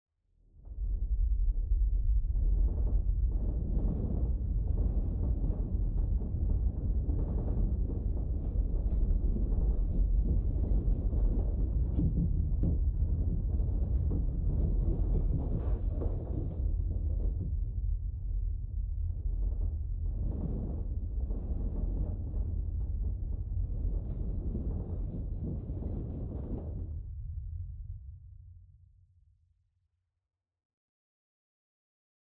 Звуки землетрясения
Этот звук был разработан для оперной постановки, чтобы имитировать землетрясение
etot_zvuk_bil_razrabotan_dlya_opernoj_postanovki_chtobi_imitirovat_zemletryasenie_xcb.mp3